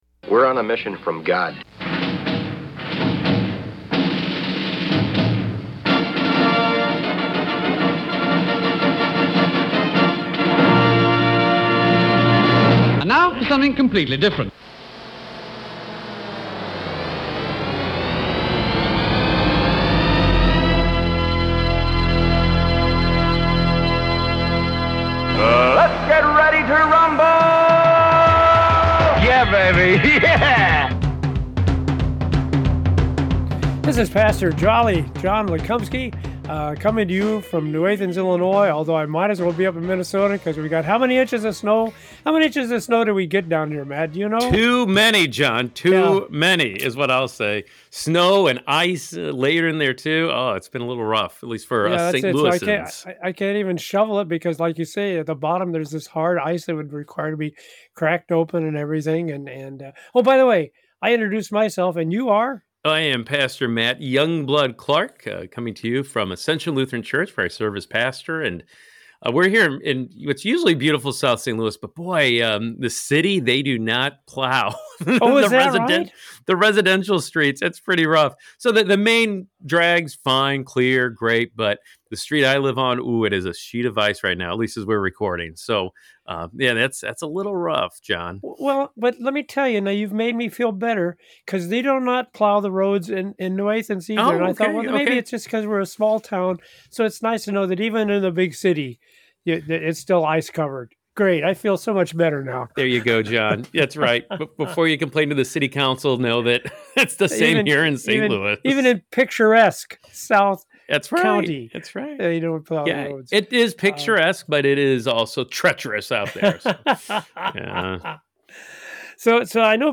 With hosts